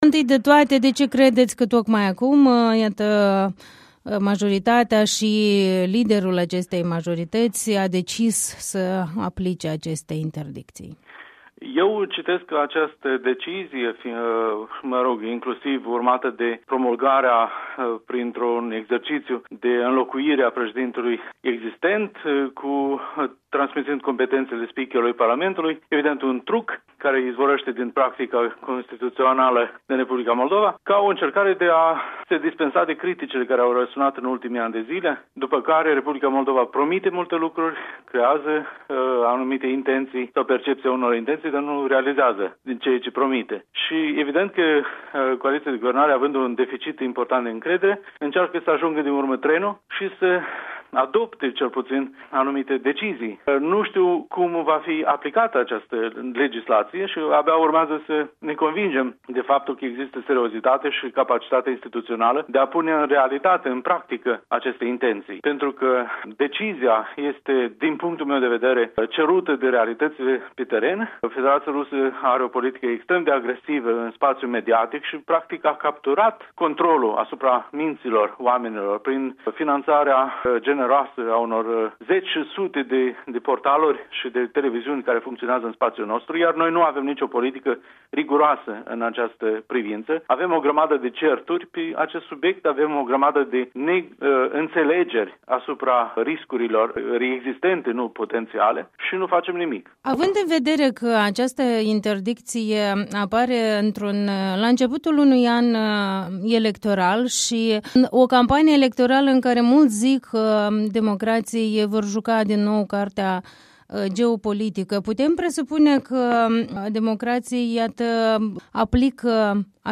Interviu cu Igor Munteanu